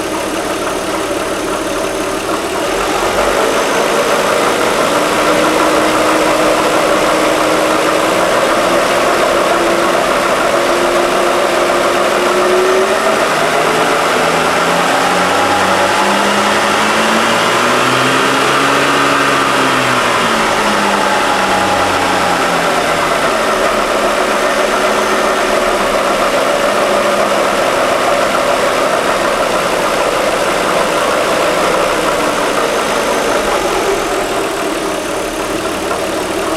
Zum Anhören zwei Samples bei warmem Motor, im Stand (kurz vor Lüfterbetrieb = etwa 80-90°C).
Vorsicht beim Anhören: Hört sich gruselig an!
1) Hoch-Runterlauf = 750 1/min - ca. 2000 1/min
vom Geräusch her ist mir ein "Klonk".."Klonk".."Klonk" aufgefallen.
Selbes Geräusch wie oben, jedoch mit Drehzahl ansteigend, bzw. abfallend.
Das "Klack" kommt hier auch deutlicher vor...
Hoch_Runterlauf.wav